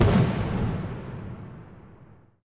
firework_distance_01.ogg